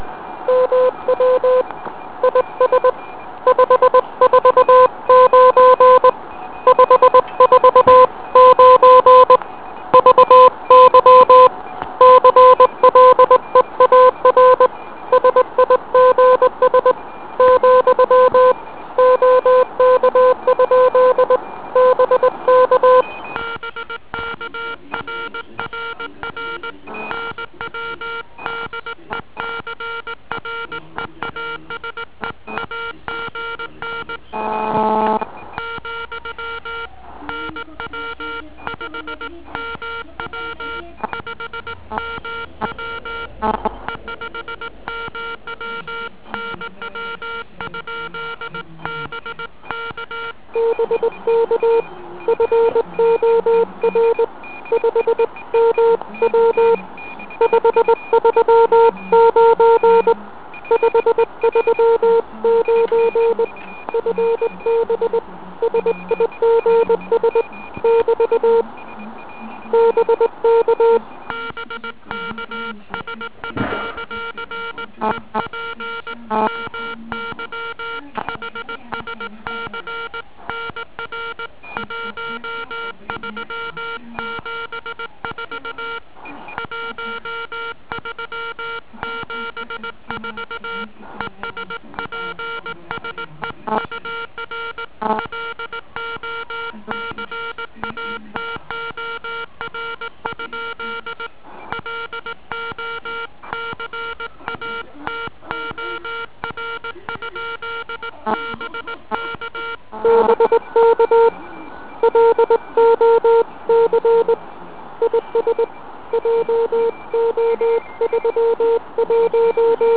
Jak se můžete přesvědčit, bylo z toho nakonec docela "ukecané" spojení HI.